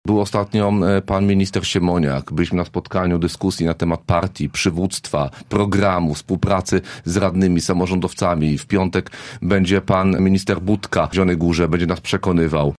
Krajowe wybory szefa PO. Komentarz Marcina Pabierowskiego
Marcin Pabierowski, z klubu radnych Koalicji Obywatelskiej w Radzie Miasta Zielona Góra, zwraca uwagę na dużą aktywność kandydatów w walce o głosy lokalnych działaczy PO zarówno w całym kraju jak i w naszym mieście: